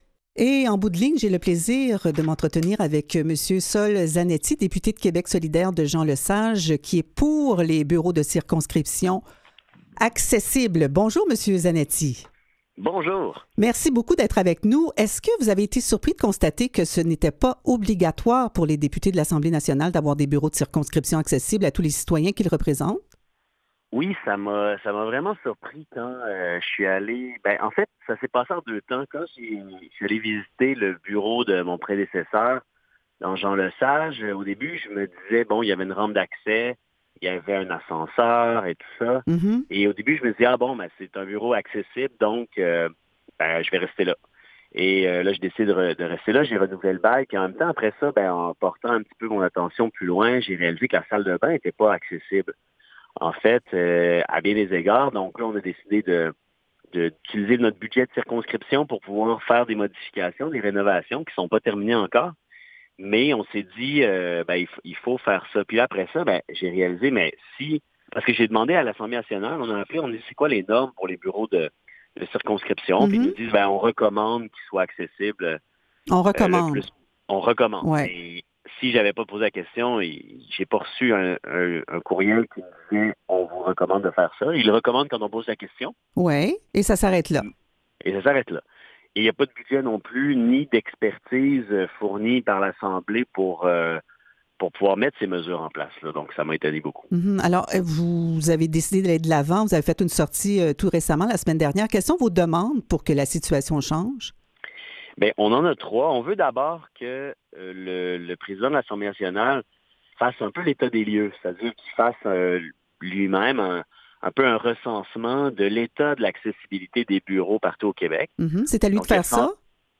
En entrevue:
Avec Sol Zanetti député de Québec solidaire de Jean-Lesage. —